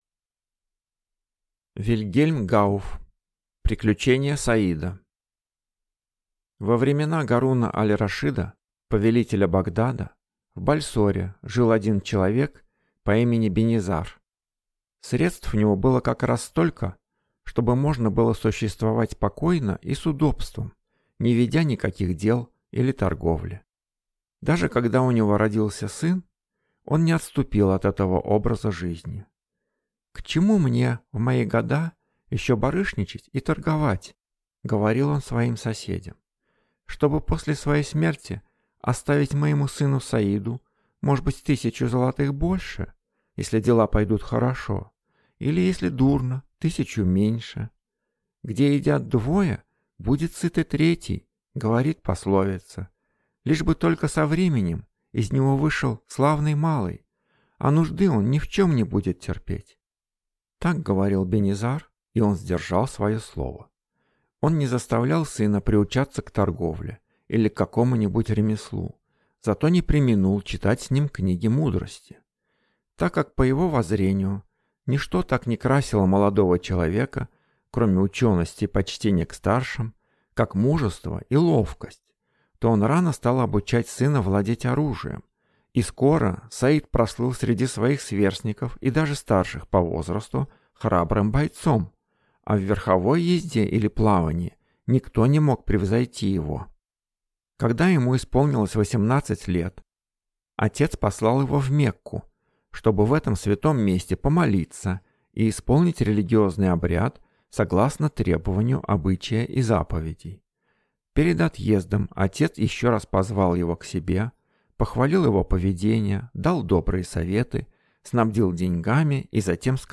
Аудиокнига Приключения Саида | Библиотека аудиокниг